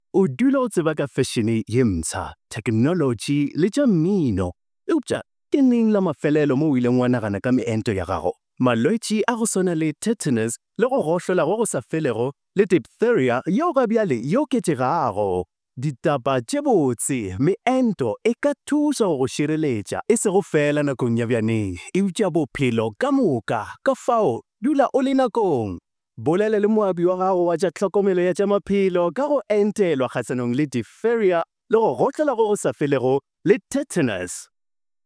commercial, conversational, energetic, friendly, promo, soothing
My demo reels